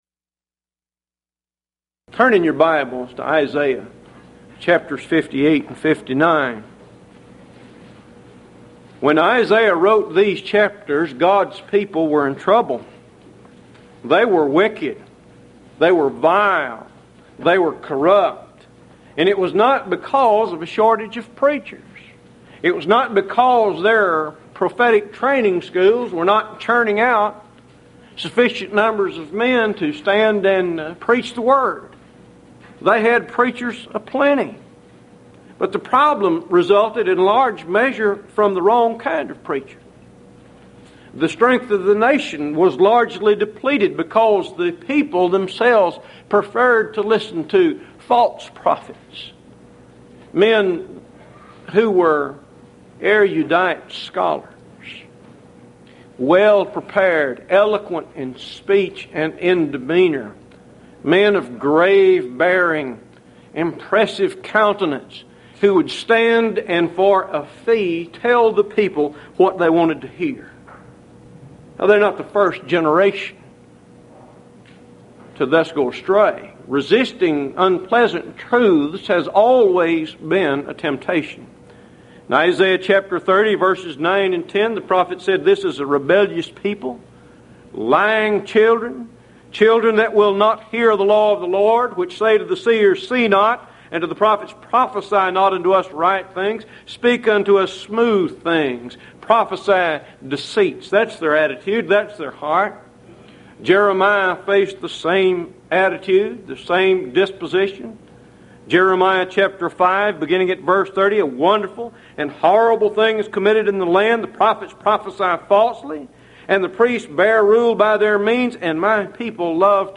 Series: Houston College of the Bible Lectures